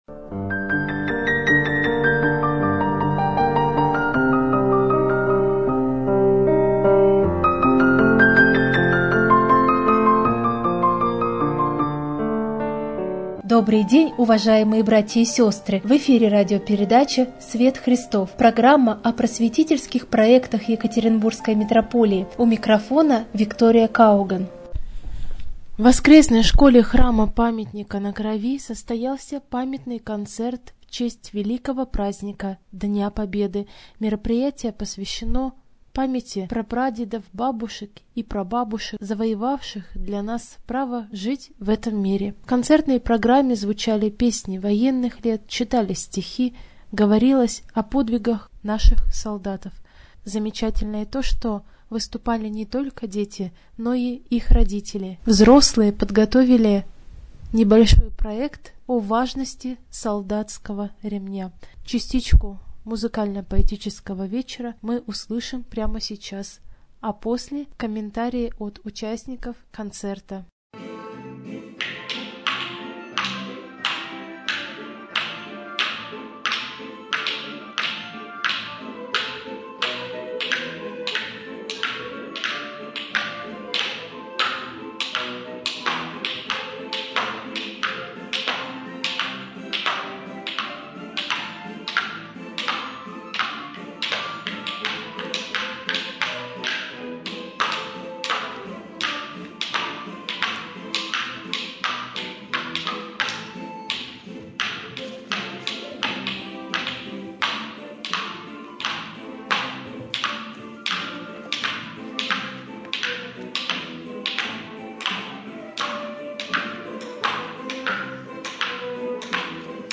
pamyatnyj_koncert_v_chest_velikogo_prazdnika_dnya_pobedy.mp3